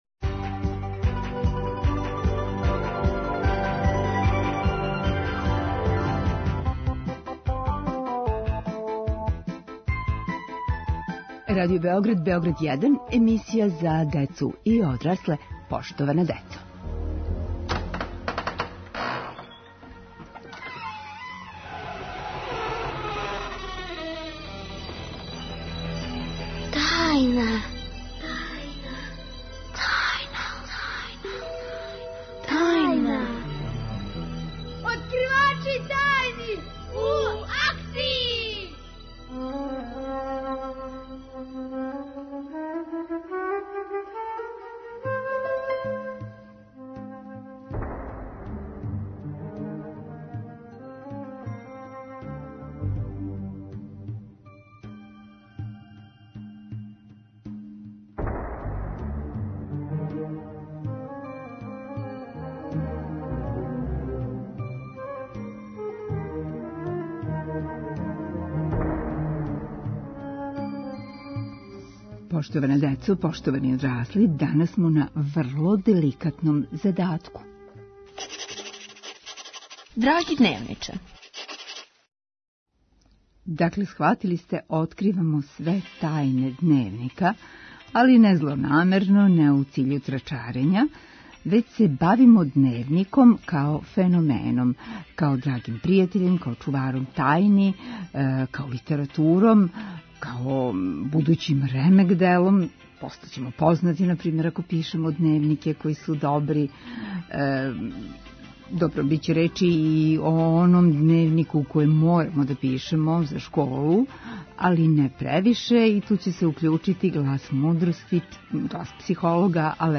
Откривамо тајне ДНЕВНИКА - или - улога дневника у одрастању. Гости деца, психолог и писац...